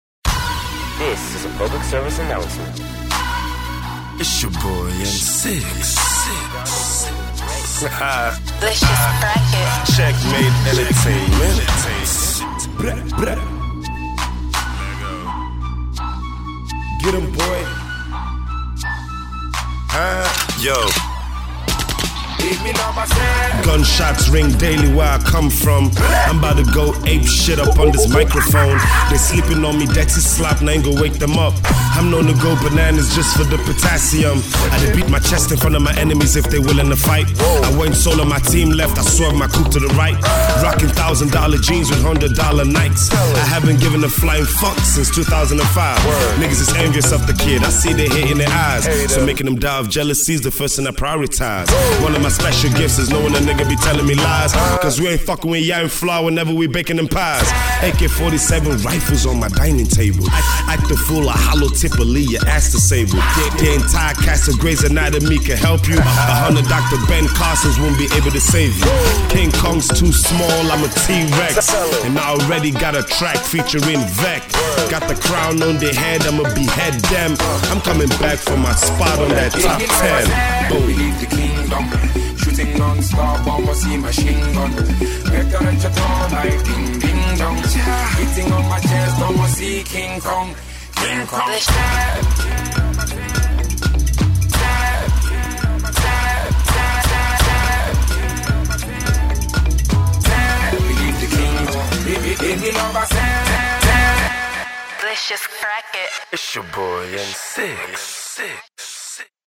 commanding and authoritative freestyle